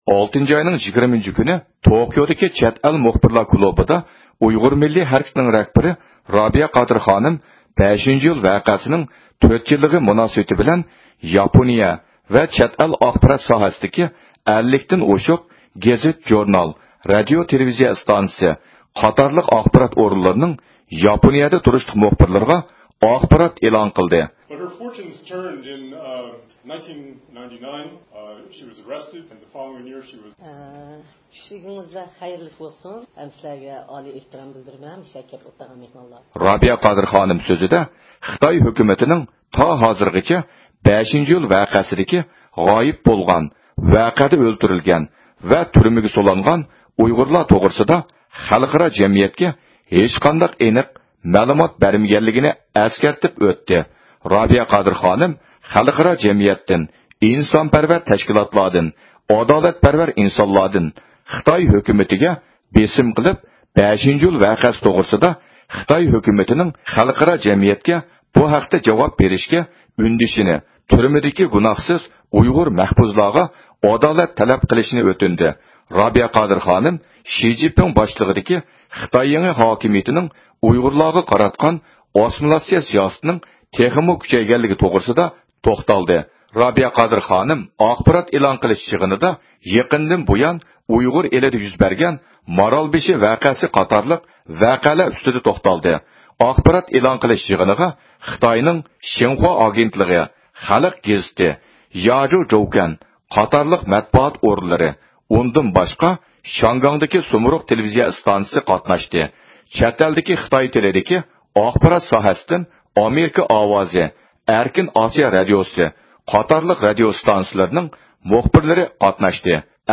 زىيارىتىمىزنى قوبۇل قىلغان رابىيە قادىر خانىم بۇ ھەقتە توختىلىپ ئۆتتى.